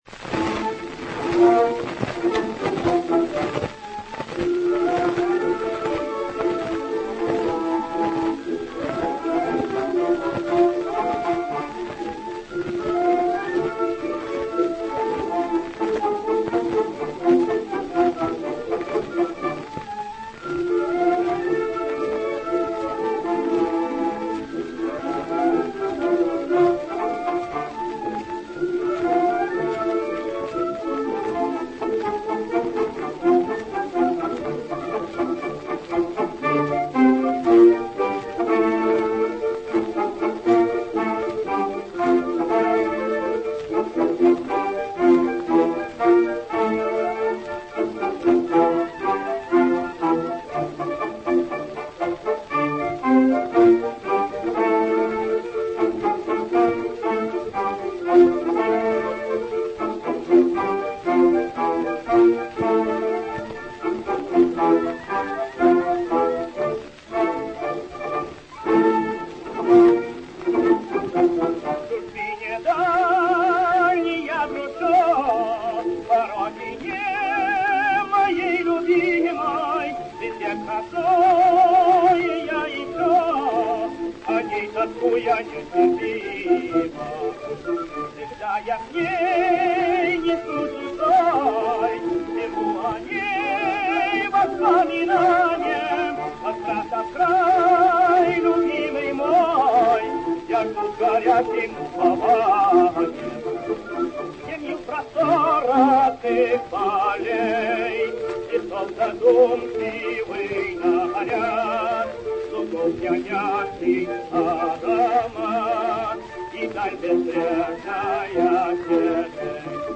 грамзапись Columbia 87400
Песня «Тоска по Родине» на слова Альб.